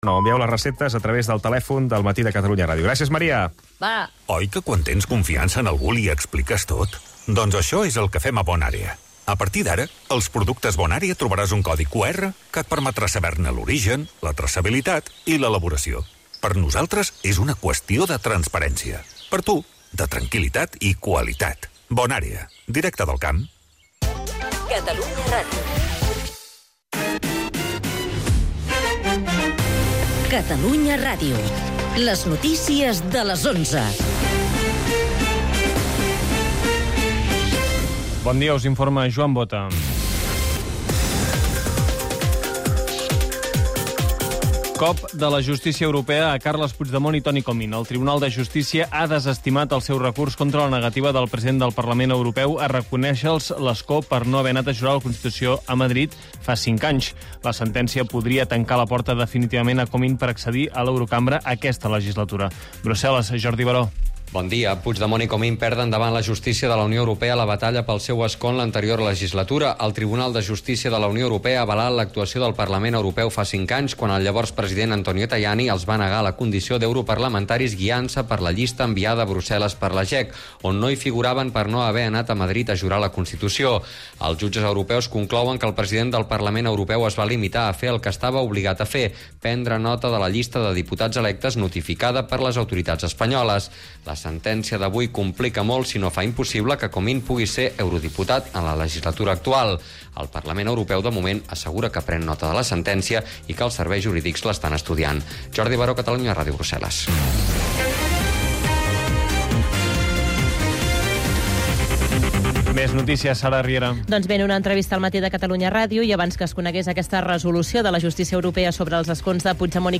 El mat, d'11 a 12 h (entrevista i humor) - 26/09/2024